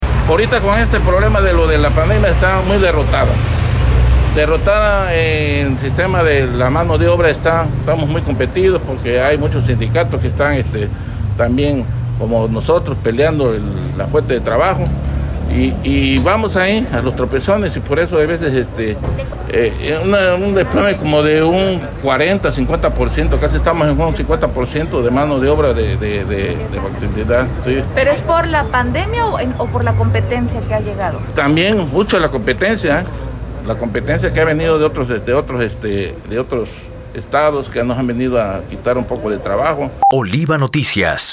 En entrevista, apuntó que el desplome ronda el 50 por ciento en la actividad de la construcción durante los últimos meses de la pandemia del COVID-19 en la entidad veracruzana.